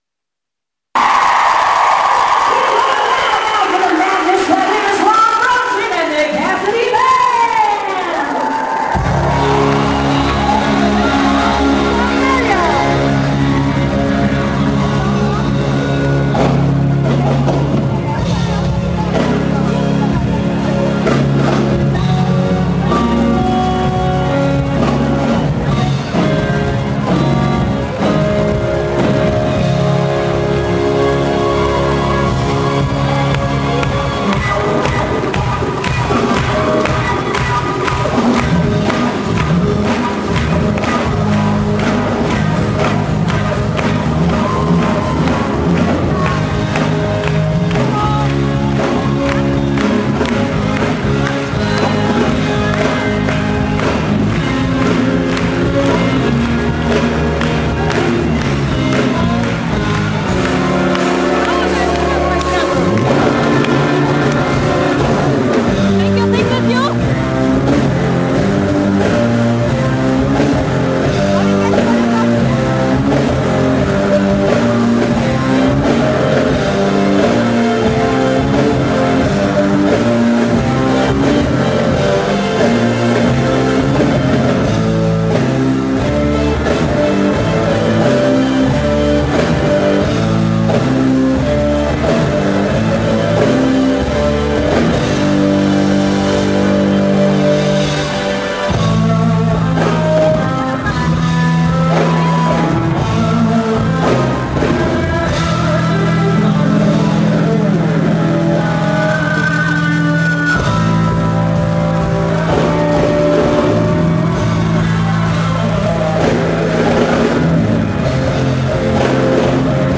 London Hammersmith Apollo May 6, 2004